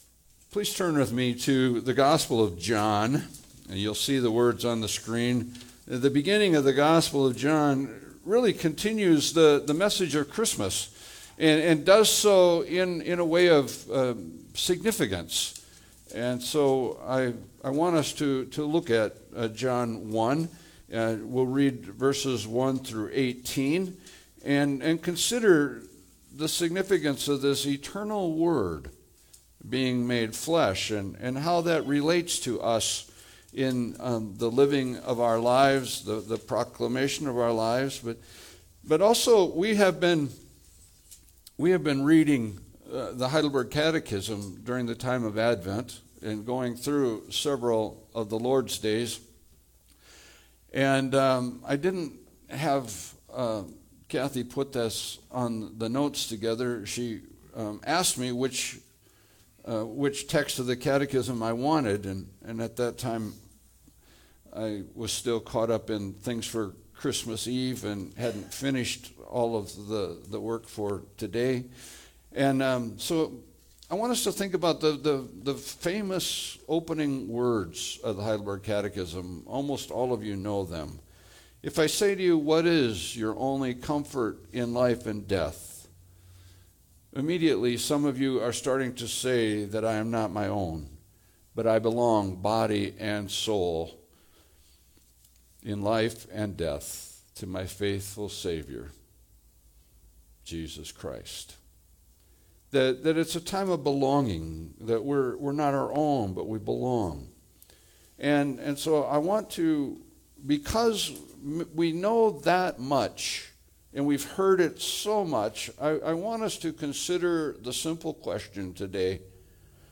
Passage: John 1:1-18 Service Type: Sunday Service « Jesus Christ